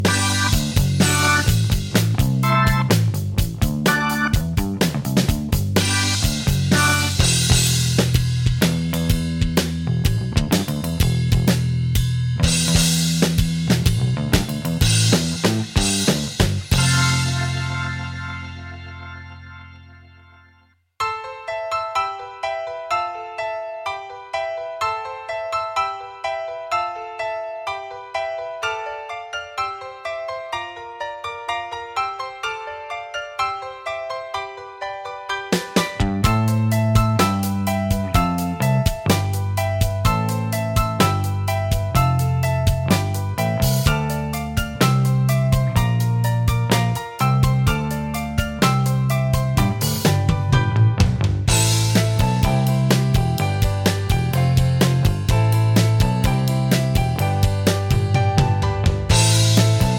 Minus Main Guitar For Guitarists 5:26 Buy £1.50